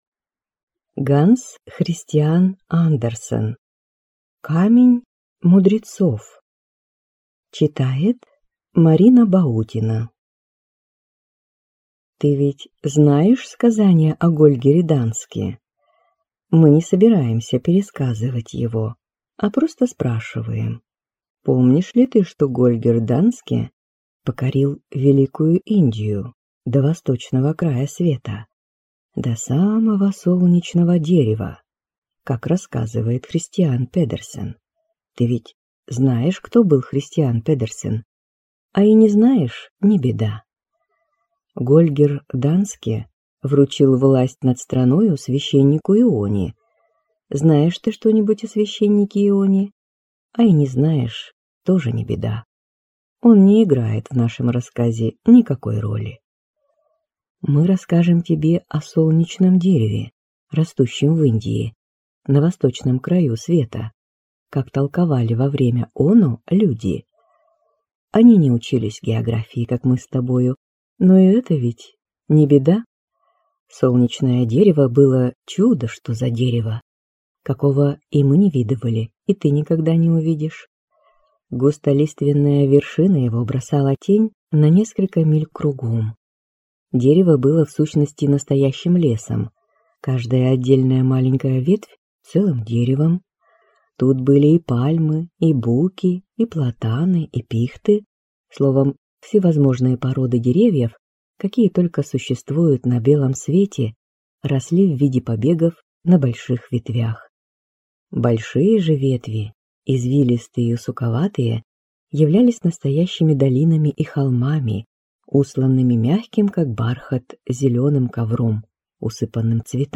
Аудиокнига Камень мудрецов | Библиотека аудиокниг